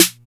808 MARCHSN.wav